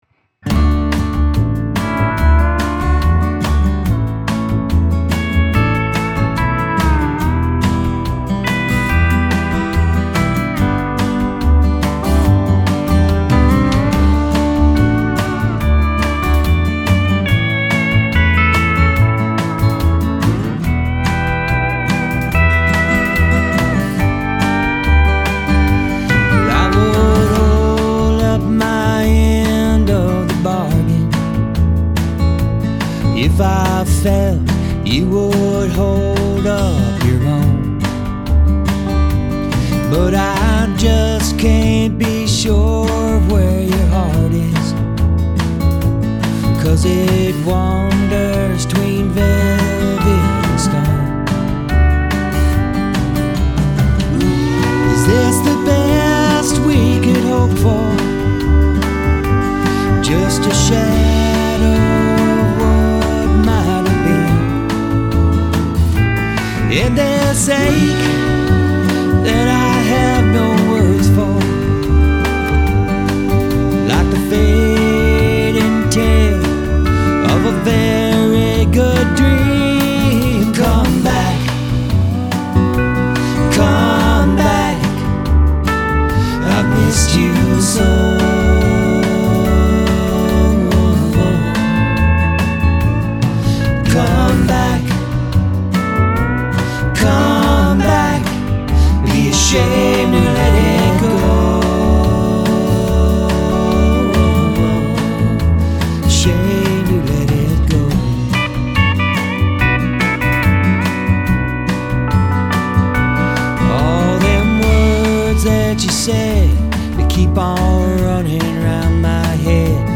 Client Recording Session
(final mix)
acoustic guitar. Recorded and mixed in Los Osos, CA